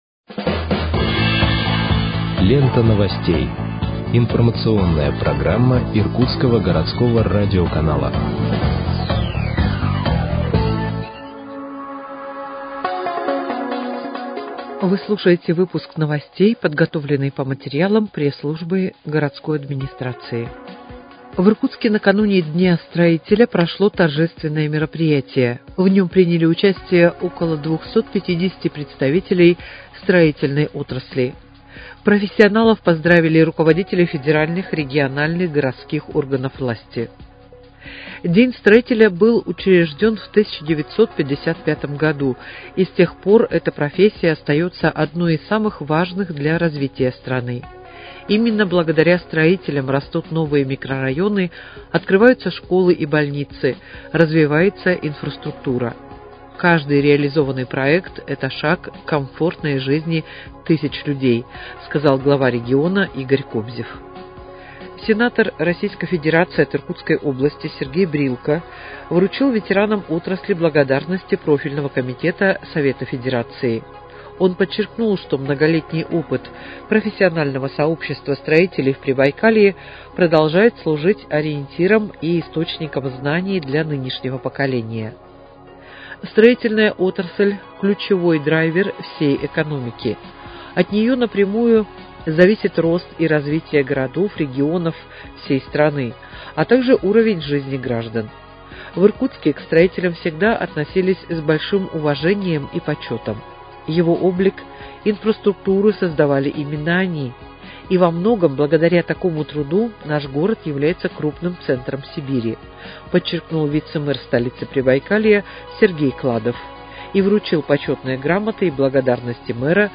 Выпуск новостей в подкастах газеты «Иркутск» от 12.08.2025 № 1